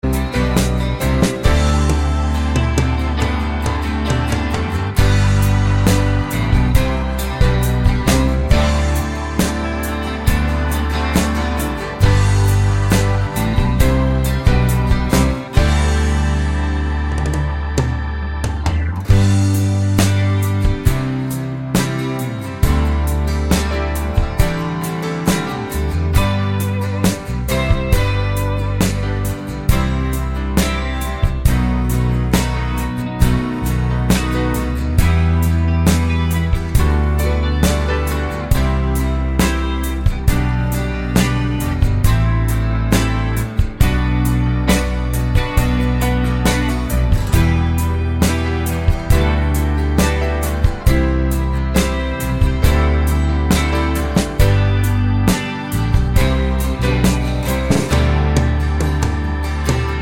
no Backing Vocals Rock 4:31 Buy £1.50